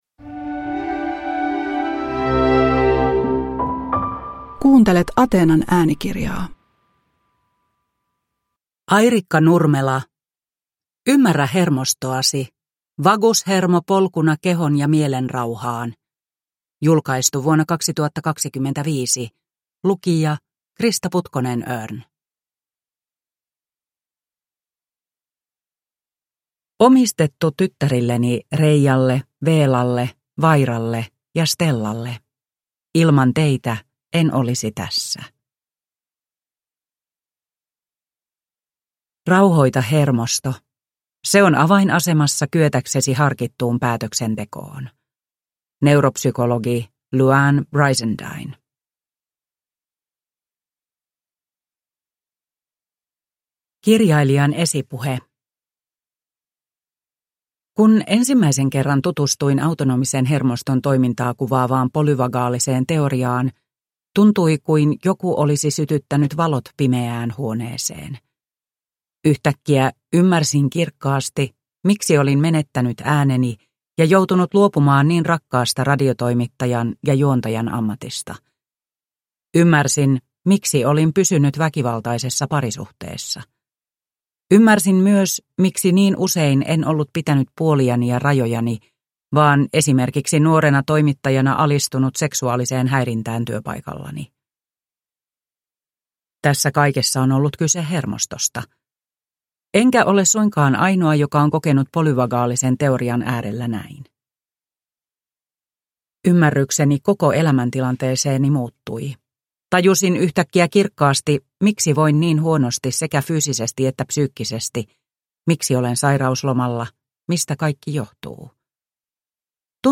Ymmärrä hermostoasi – Ljudbok